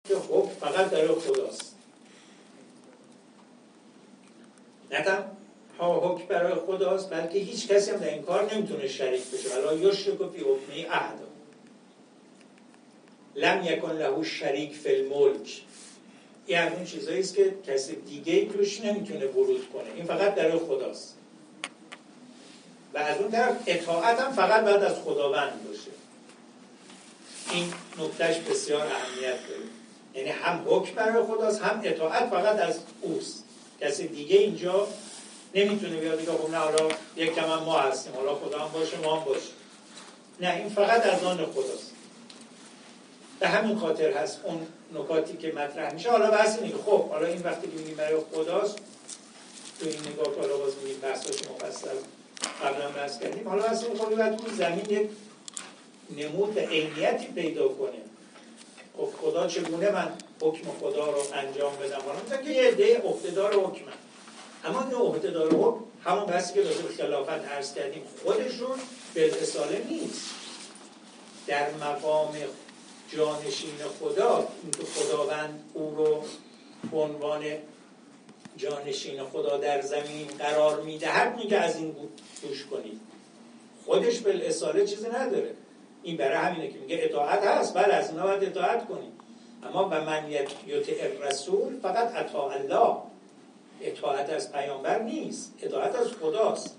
ایمان و عمل صالح دو مؤلفه خلیفةاللهی انسان بر زمین است + صوت و عکسبه گزارش خبرنگار ایکنا، نشستی از سلسله‌نشست‌های حکمت سیاسی اسلام در قرآن با سخنرانی سعید جلیلی؛  عضو مجمع تشخیص مصلحت نظام شامگاه سه‌شنبه، 5 دی‌ماه، در سالن طاهره صفارزاده حوزه هنری سازمان تبلیغات اسلامی برگزار شد.